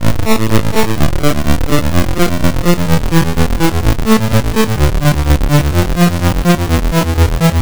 Lo-Bit Arp A 126.wav